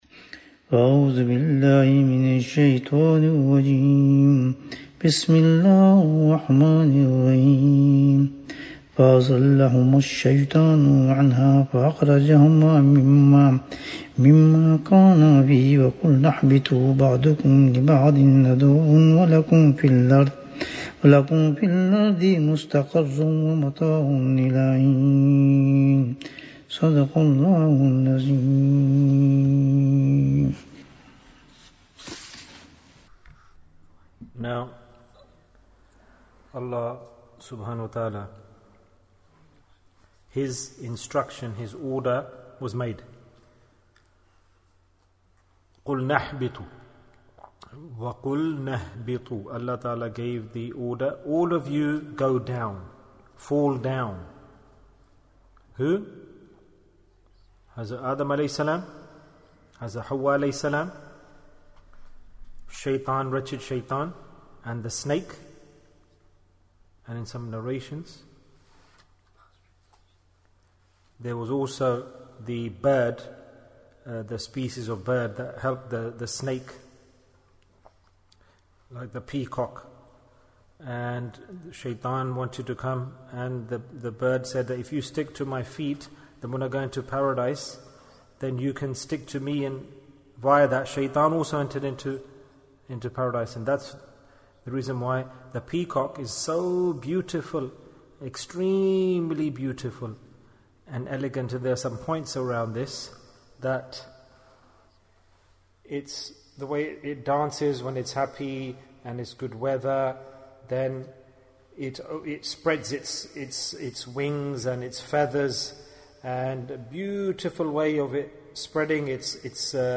Is This World Beneficial For Us? - Dars 40 Bayan, 46 minutes5th August, 2020